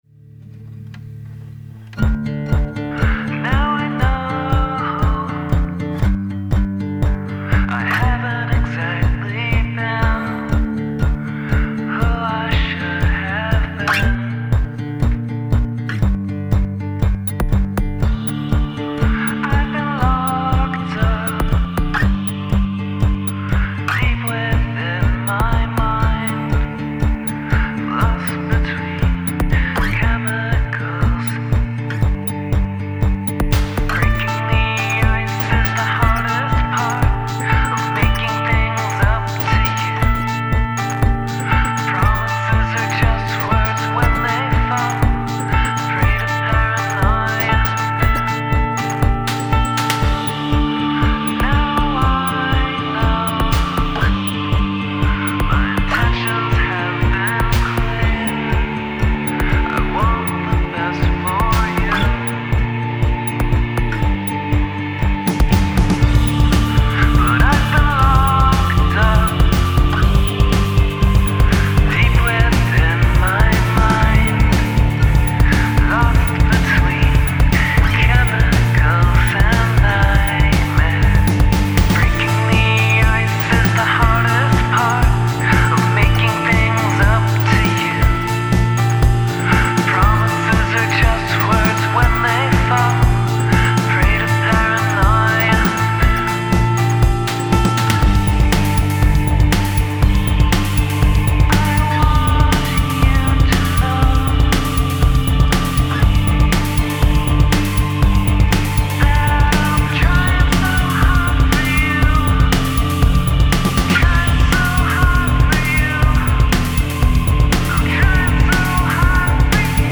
Significant Use of Shouting